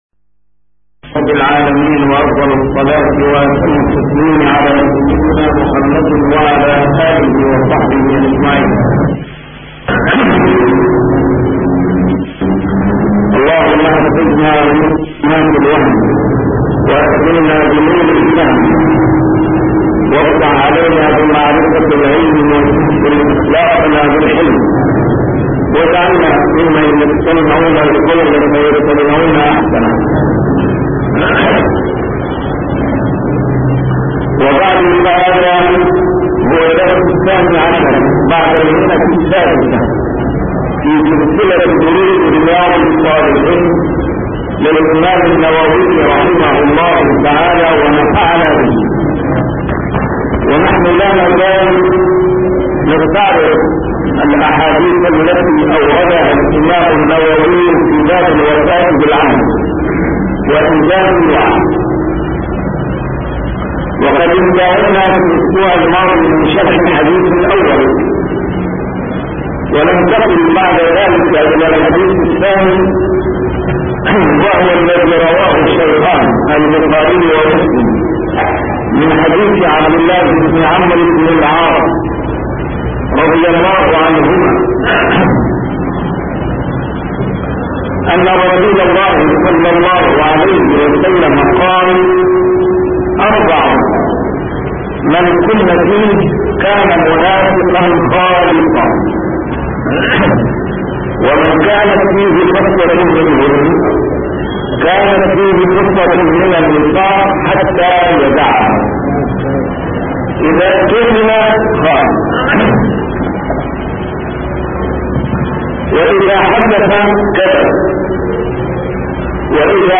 A MARTYR SCHOLAR: IMAM MUHAMMAD SAEED RAMADAN AL-BOUTI - الدروس العلمية - شرح كتاب رياض الصالحين - 612- شرح رياض الصالحين: الوفاء بالعهد وإنجاز الوعد